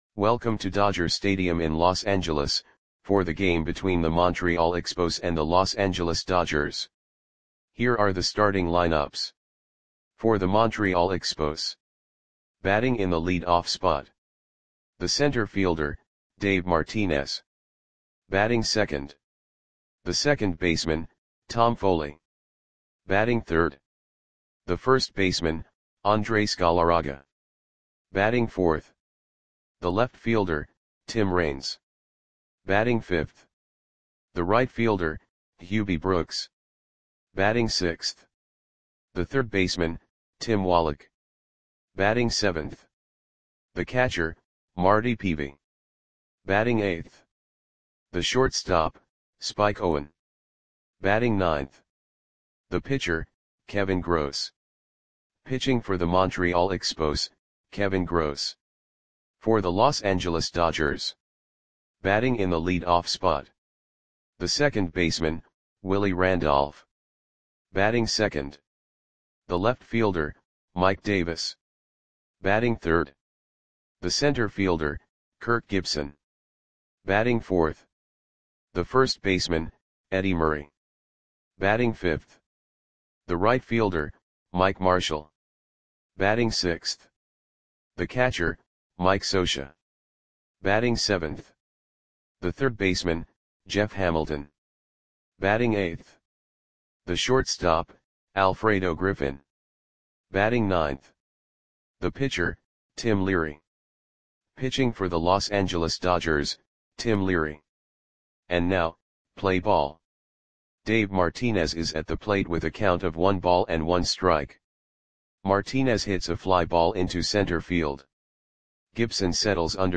Click the button below to listen to the audio play-by-play.
Expos 3 @ Dodgers 2 Dodger StadiumMay 29, 1989 (No Comments)